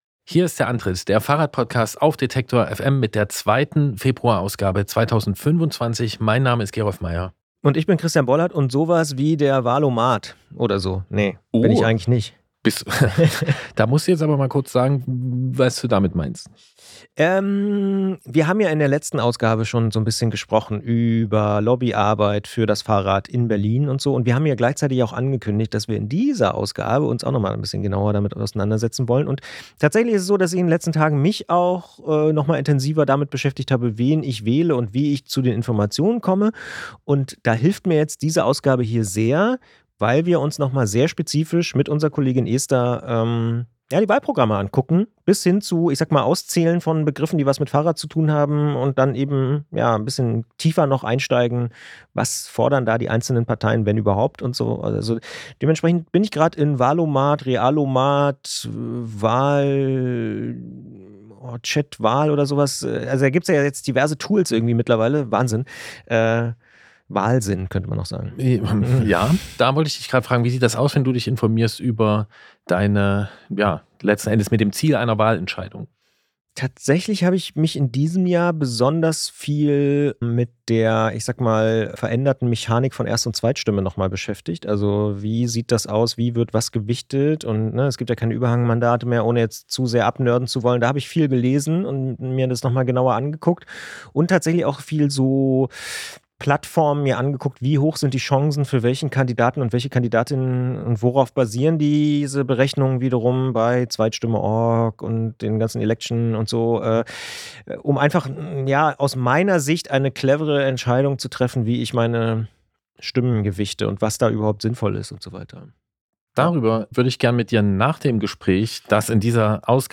Studiogespräch zur Bundestagswahl